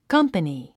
発音
kʌ’mpəni　カンパニィ